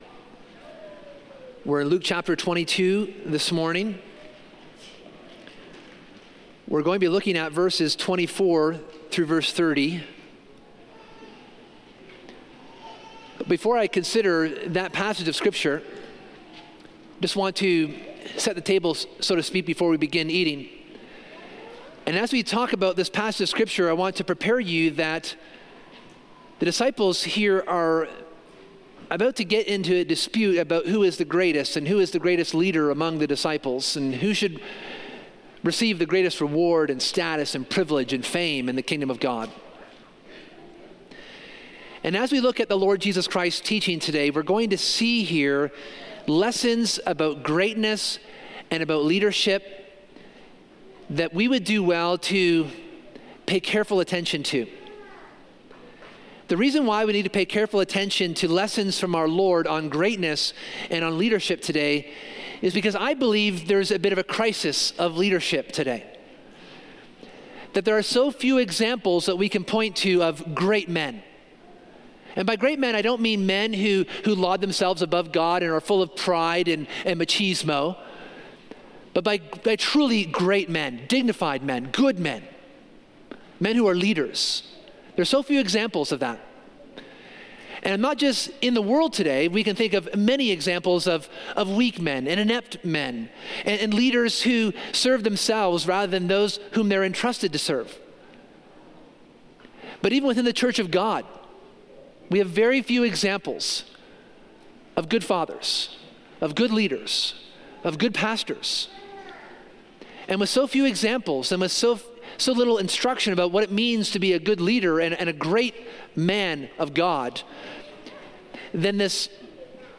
This sermon looks at Luke 22:24-30, where the disciples’ dispute over greatness reveals a timeless truth: true leadership is rooted in humility, service, and faithfulness.